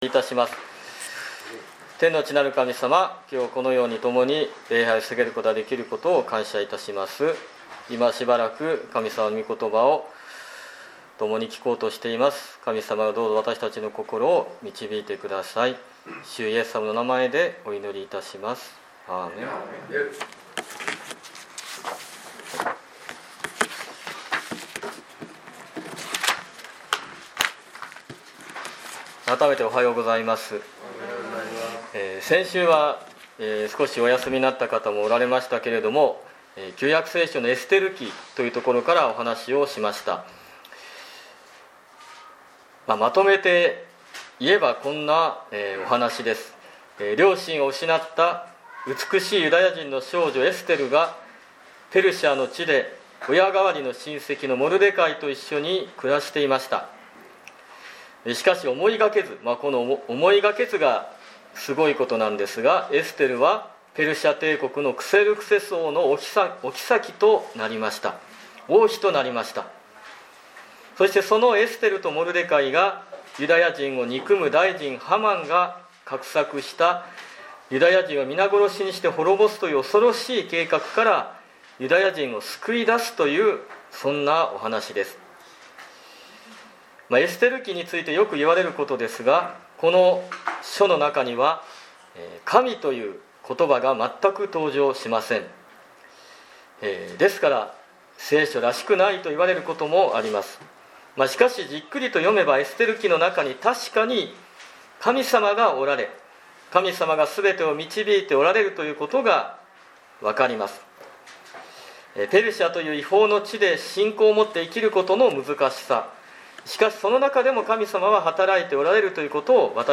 2022年5月22日ファミリー礼拝